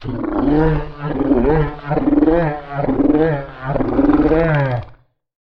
Хотя его существование не доказано, эти аудиозаписи помогут вам представить его голос: от глухого рычания до странных шорохов.
Звуки йети: голос снежного человека